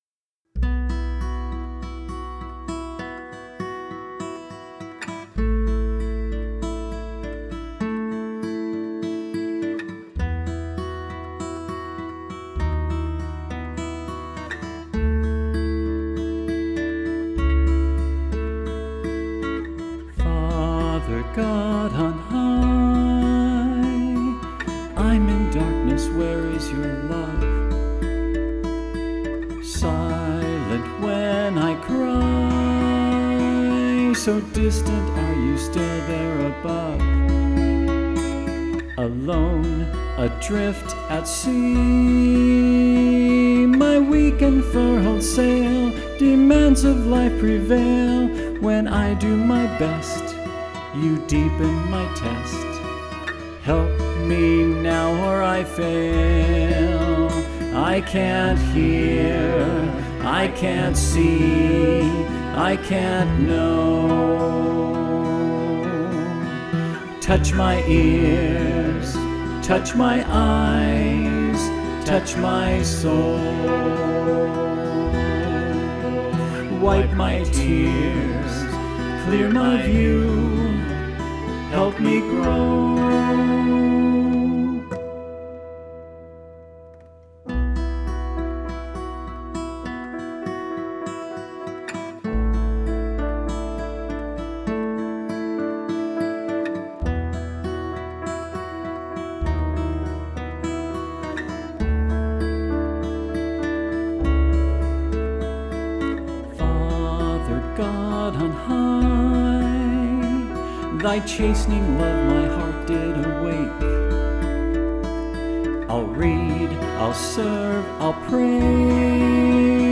Vocal Solo Medium Voice/Low Voice